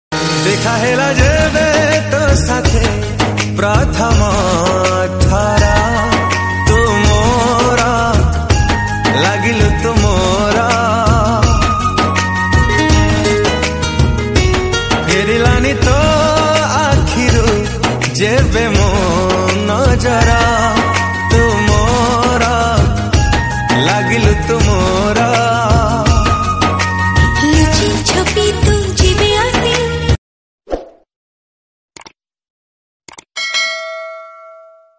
A captivating melody imbued with cultural colors